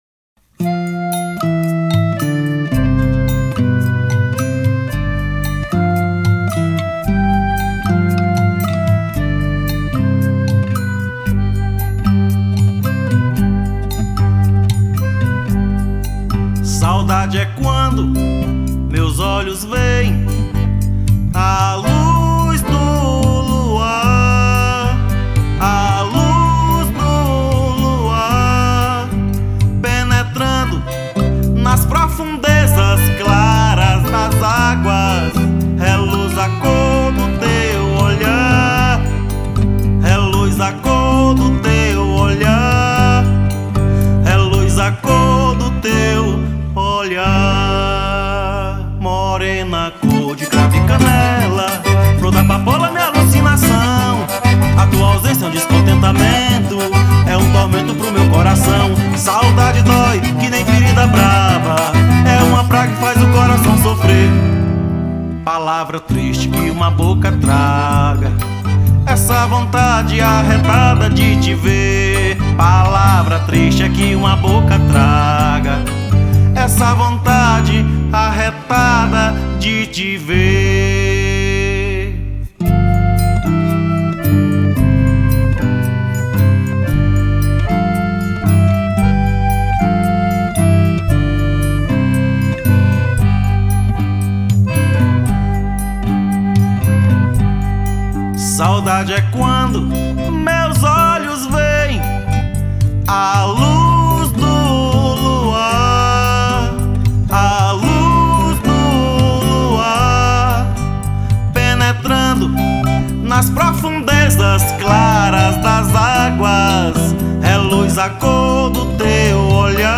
1595   03:00:00   Faixa:     Canção Nordestina